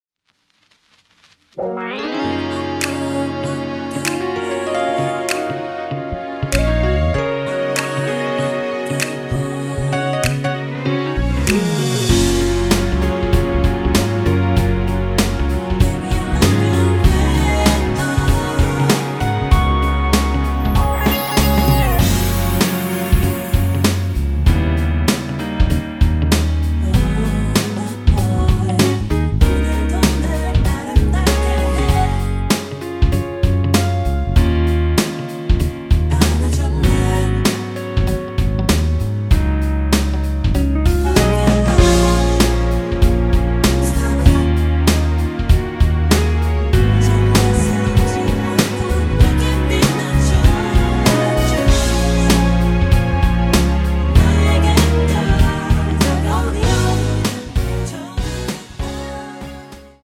(-2) 내린 코러스 포함된 MR 입니다.(미리듣기 참조)
Gb
앞부분30초, 뒷부분30초씩 편집해서 올려 드리고 있습니다.
중간에 음이 끈어지고 다시 나오는 이유는